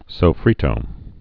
(sō-frētō, sə-)